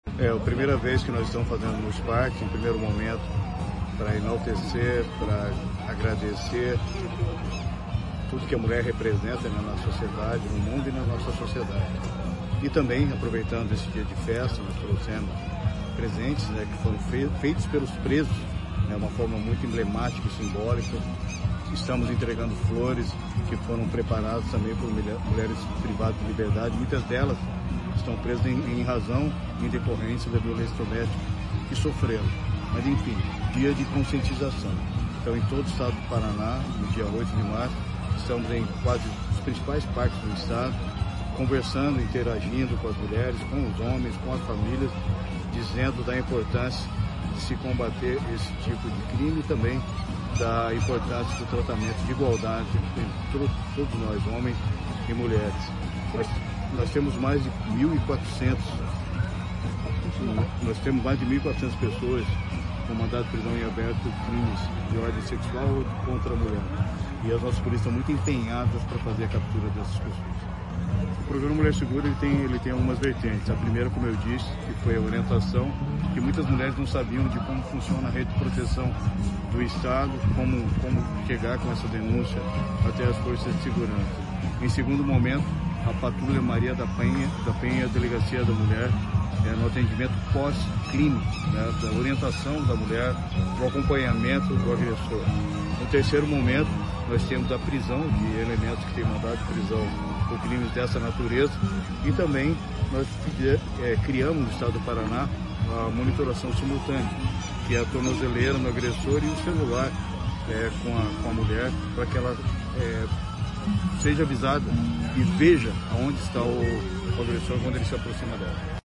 Sonora do secretário de Segurança Pública, Hudson Teixeira, sobre conscientização sobre o combate à violência contra mulher